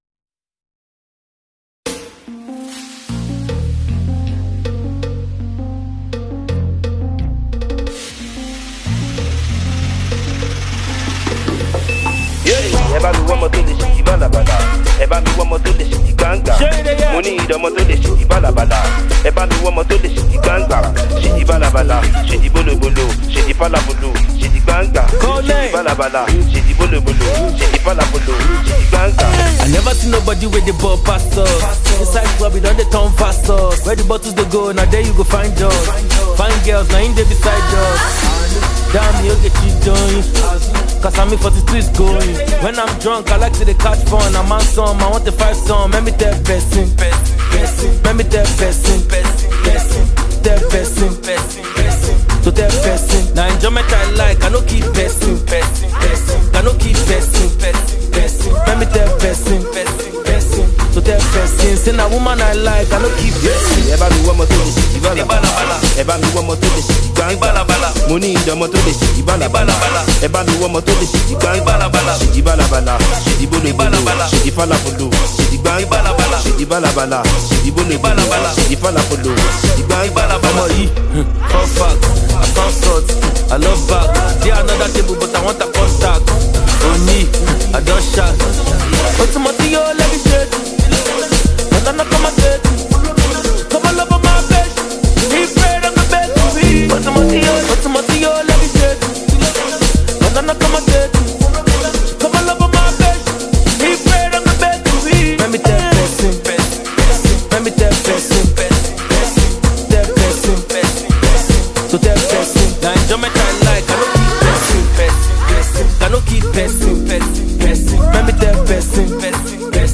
highly energetic and infectious track